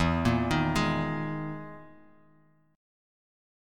EM7b5 chord